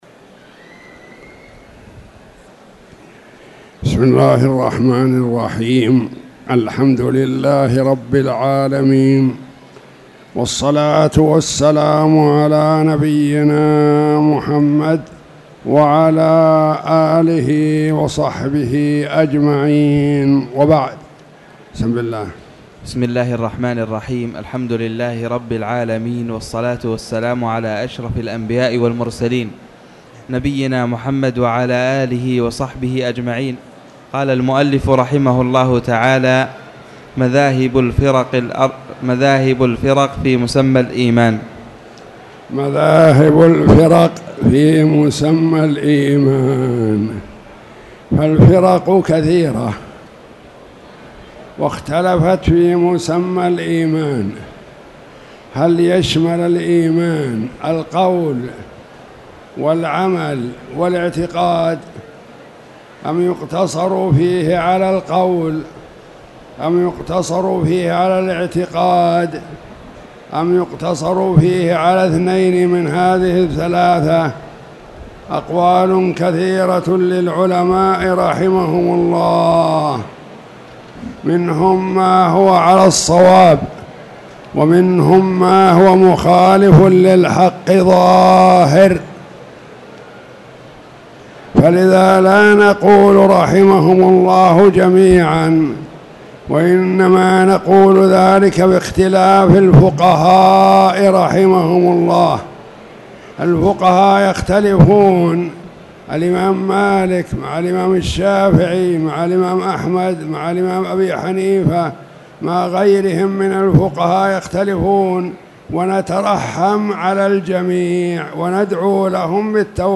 تاريخ النشر ٢٠ شوال ١٤٣٧ هـ المكان: المسجد الحرام الشيخ